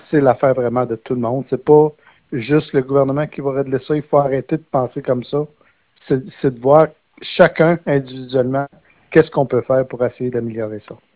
En entrevue, le député de Nicolet-Bécancour a mentionné qu’avant la conférence de presse du 14 novembre dernier, personne ne lui avait parlé de cette problématique.